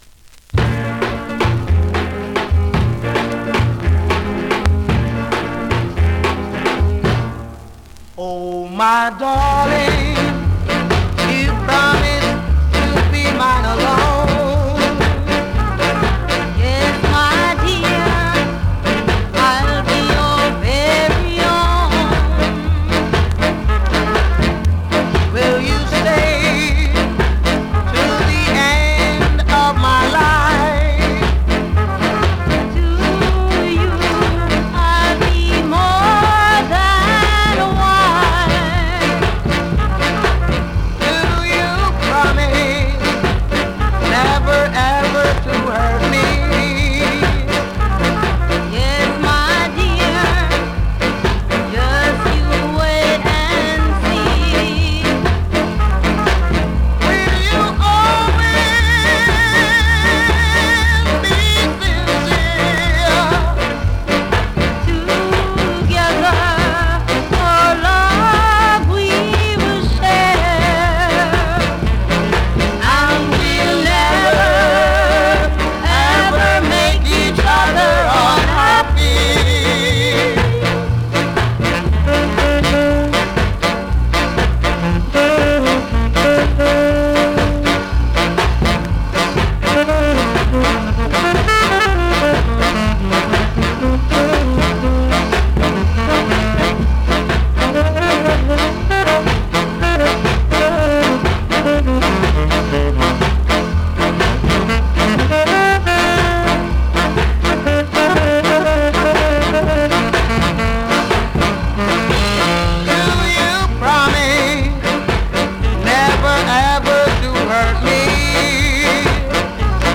SKA
スリキズ、ノイズかなり少なめの